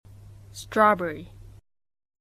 英語と米語の発音
アメリカの発音 - 果物Part 2